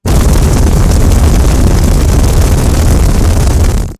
Roaring Flames
Intense roaring flames consuming material with rushing air and snapping wood
roaring-flames.mp3